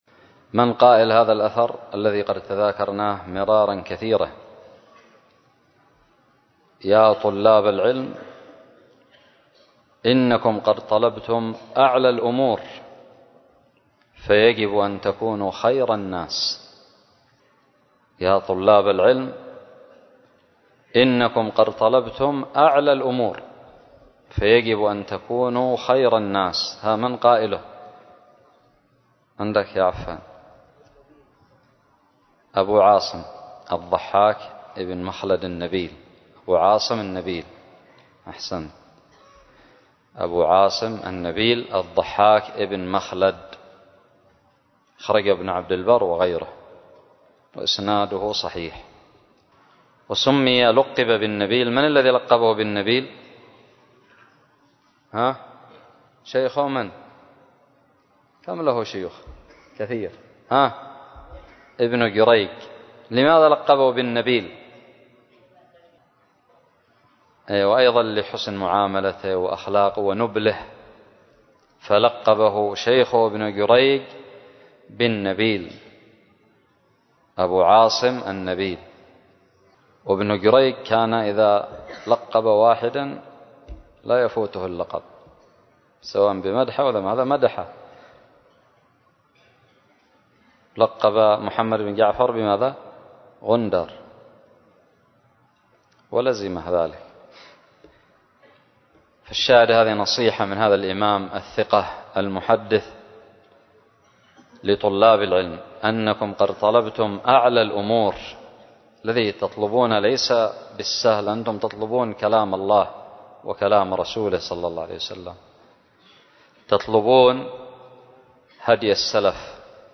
الدرس الثامن والعشرون من شرح كتاب تذكرة السامع والمتكلم 1444هـ
ألقيت بدار الحديث السلفية للعلوم الشرعية بالضالع